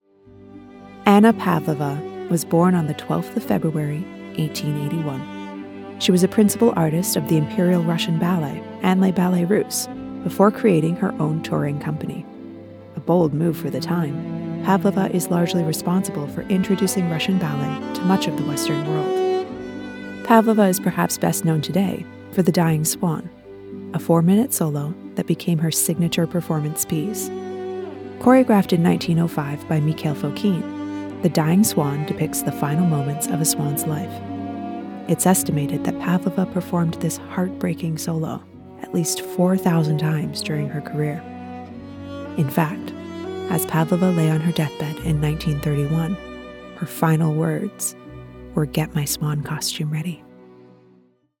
Englisch (Kanadisch)
Dokumentarfilme
Hauptmikrofon: Aston Spirit
Studio: Maßgeschallte, permanente Heimkabine (-79dB Geräuschpegel)
Im mittleren Alter